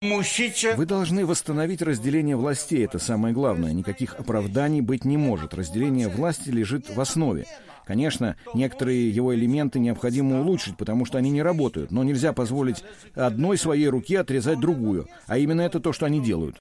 Лех Валенса, бывший президент Польши и в прошлом лидер антикоммунистической оппозиции, присоединился к протестам против планов правящей партии поставить судебную систему под политический контроль. 73-летний Валенса выступил на митинге в Гданьске накануне, 22 июля, и заявил толпе, что следует...